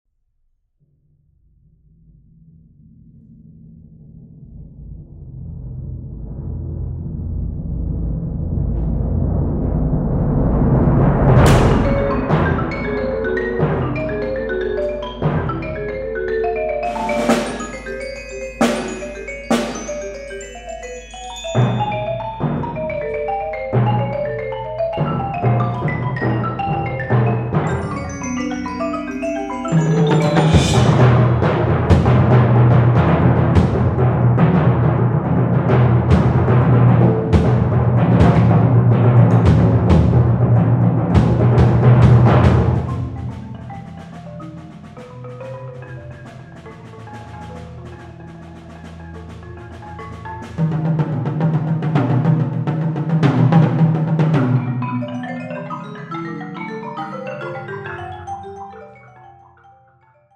Percussion Ensemble - Seven or more Players